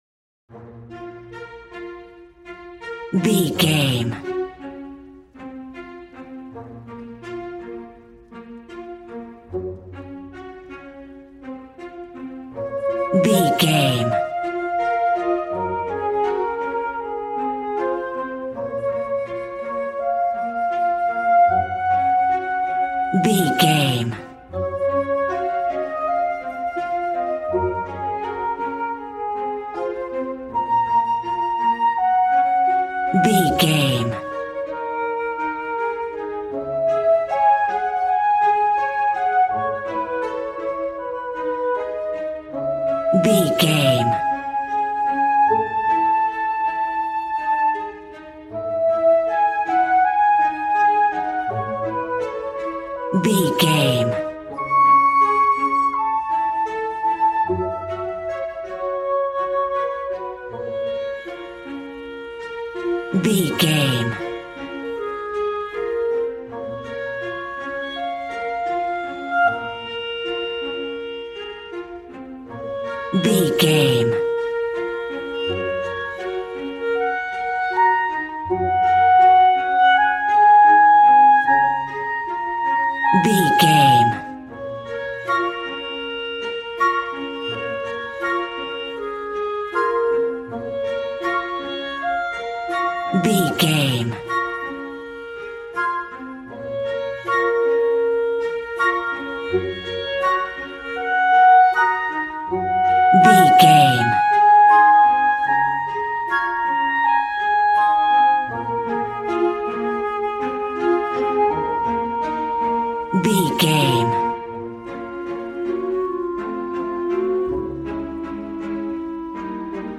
A warm and stunning piece of playful classical music.
Regal and romantic, a classy piece of classical music.
Aeolian/Minor
B♭
regal
piano
violin
strings